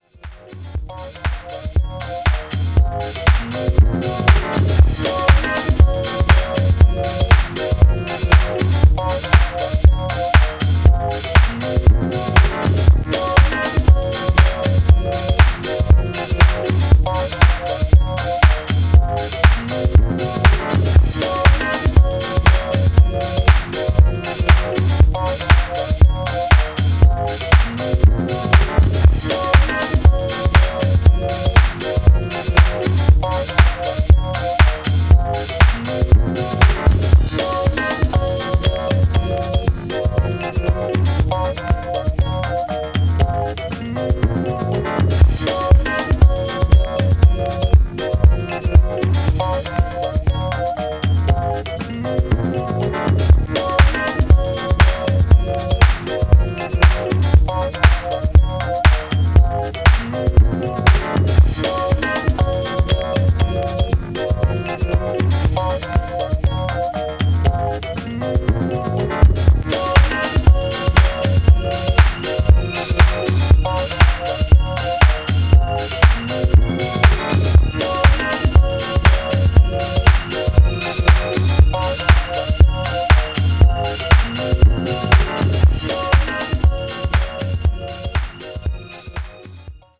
ラフでミニマルなディスコサンプルと楽観的で時に調子外れのメロディー、もっさりと足取りを奪うグルーヴ。
何はともあれディスコ/ハウス史に残る、キュートで親しみやすくクレイジーな金字塔です。